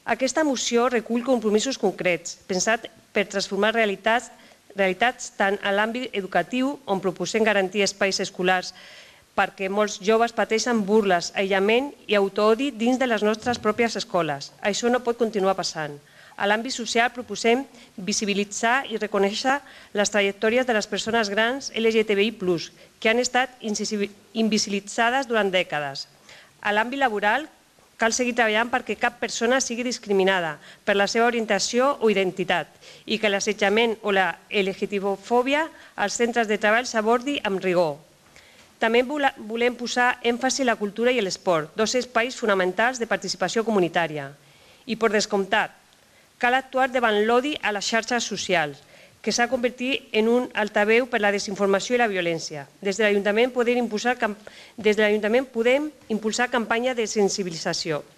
Denuncia “l’auge del discurs d’odi i la desinformació en xarxes socials”, i clama “per impulsar campanyes de sensibilització i alfabetització mediàtica”, com expressava en la seva intervenció la regidora d’Igualtat de l’Ajuntament de Martorell, Remedios Márquez.
Remedios Márquez, regidora d'Igualtat